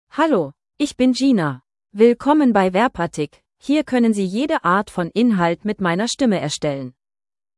Gina — Female German (Germany) AI Voice | TTS, Voice Cloning & Video | Verbatik AI
Gina is a female AI voice for German (Germany).
Voice sample
Female
Gina delivers clear pronunciation with authentic Germany German intonation, making your content sound professionally produced.